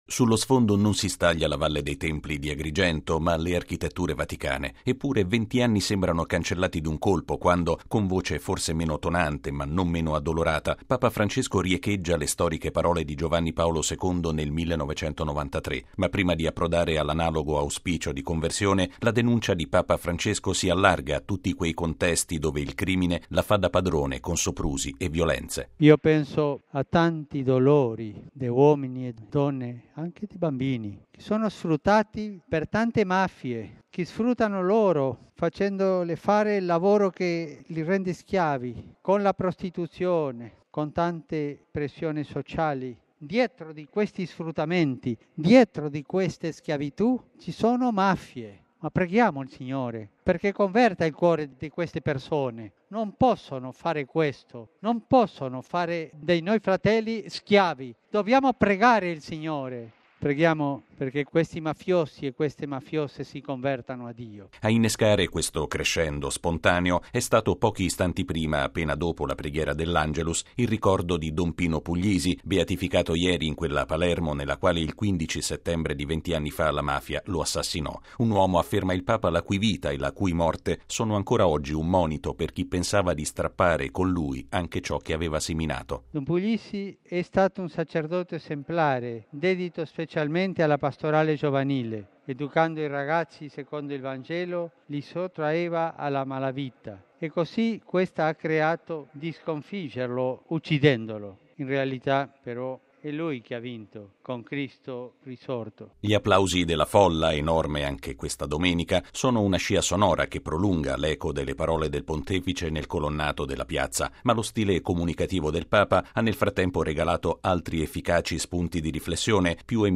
Questa preghiera di Papa Francesco, salutata da salve di applausi, ha caratterizzato l’Angelus di questa mattina in Piazza San Pietro.
Sullo sfondo non si staglia la Valle dei Templi di Agrigento ma le architetture vaticane, eppure 20 anni sembrano cancellati d’un colpo quando, con voce forse meno tonante ma non meno addolorata, Papa Francesco riecheggia le storiche parole di Giovanni Paolo II nel 1993.
Gli applausi della folla, enorme anche questa domenica, sono una scia sonora che prolunga l’eco delle parole del Pontefice nel Colonnato della Piazza.